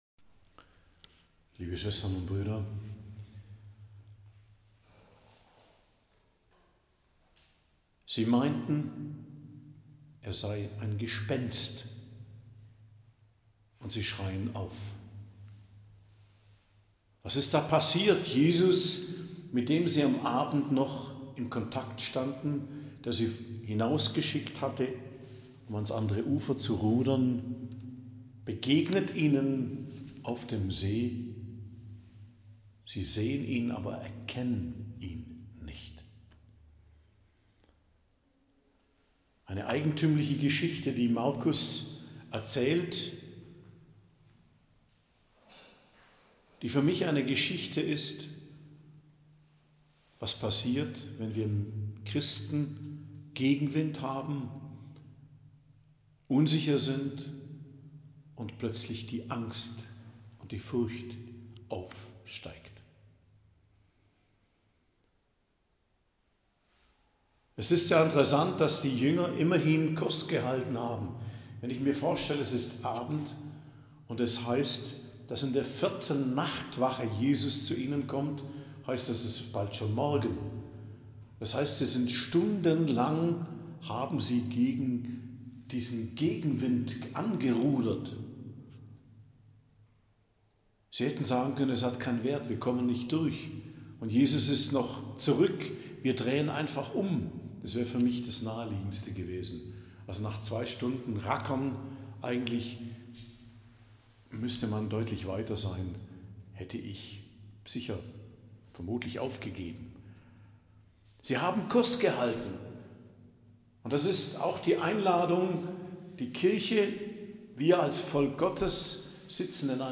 Predigt am 9. Januar, 9.01.2025